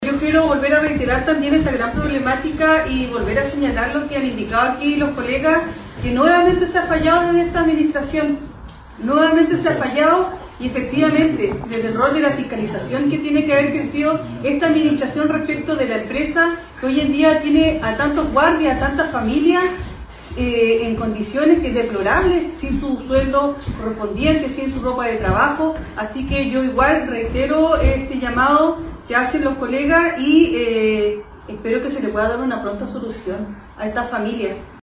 En una reciente sesión de Concejo municipal se presentó también al alcalde Baltazar Elgueta el problema social que significa este abandono de los operarios, como lo indicó la concejala Yohana Morales, quien reclamó por la falta de fiscalización sobre la empresa que incumplió todas sus obligaciones.